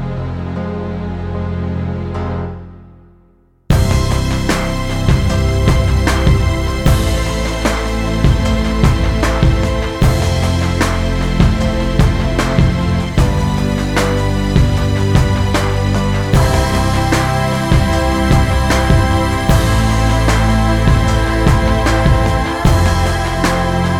One Semitone Down Pop (2000s) 4:02 Buy £1.50